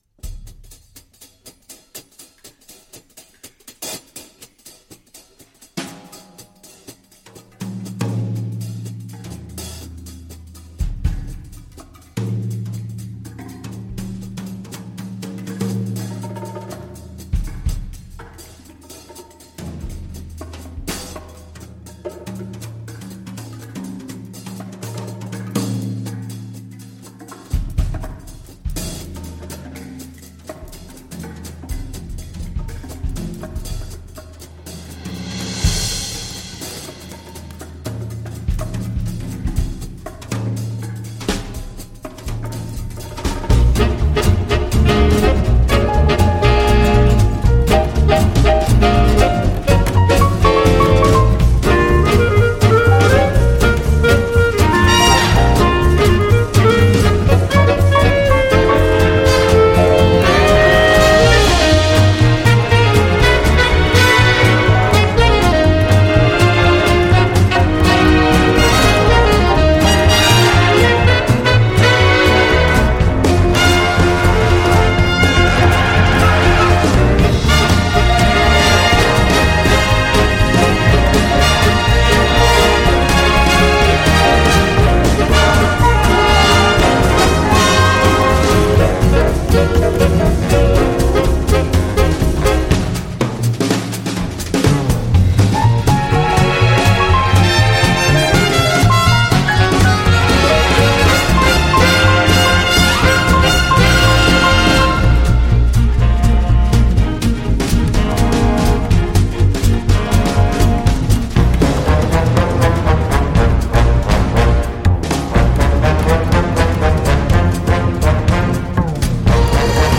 orchestre et big band jazz